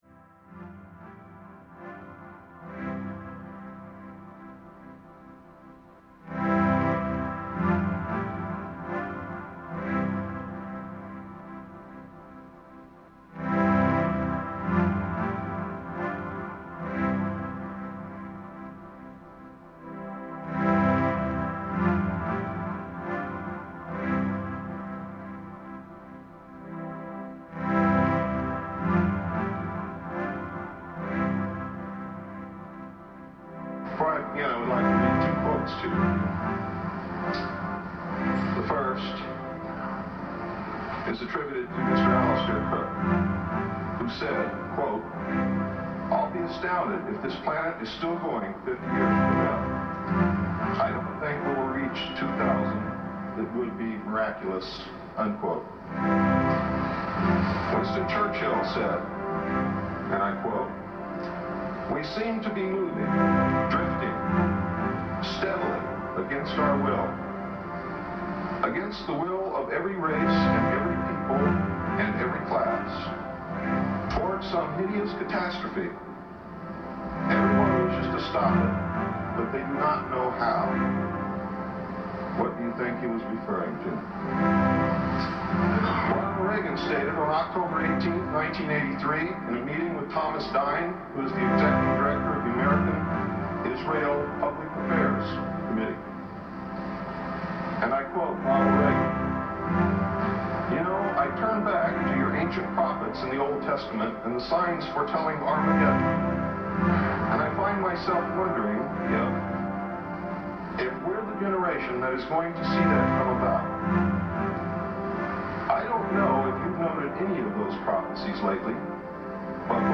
Ambient/world dream field.
Tagged as: Ambient, New Age, Industrial, Remix, Space Music